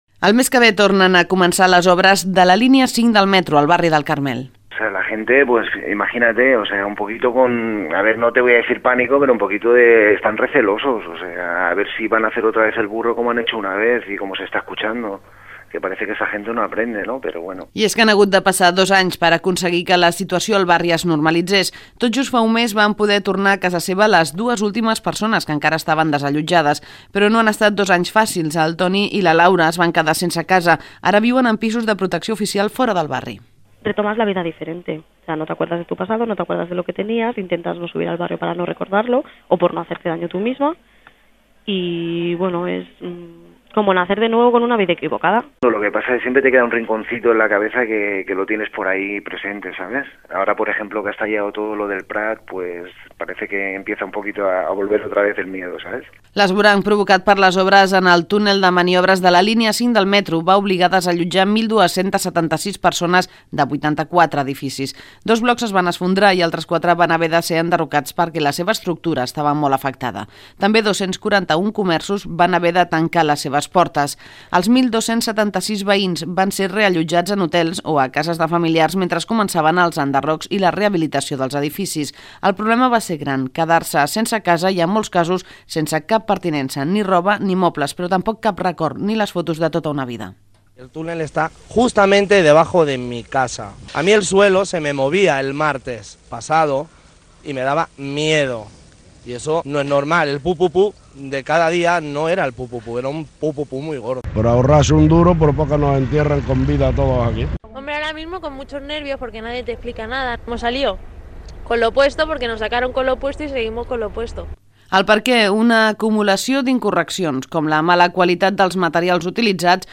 Informatius: Metro del Carmel, dos anys de l'accident .